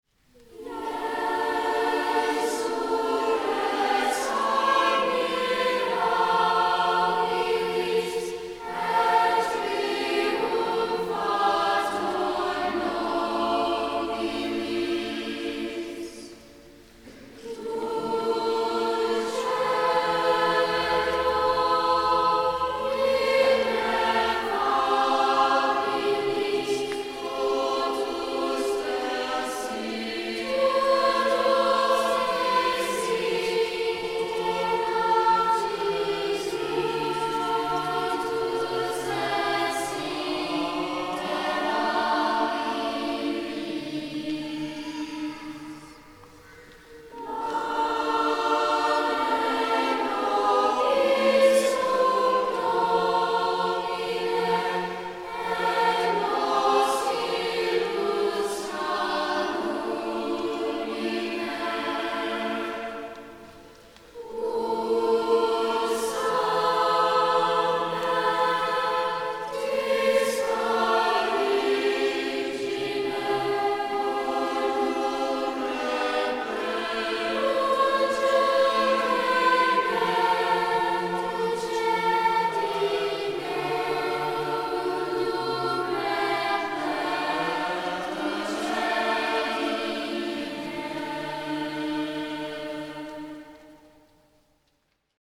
Mp3 Download • Live Children’s Choir Rec.
Ave Maria Parish Children’s Choir
What makes this motet so useful for young choirs is its serene, floating lines and gentle imitation—hallmarks of Palestrina’s style that make the piece surprisingly accessible.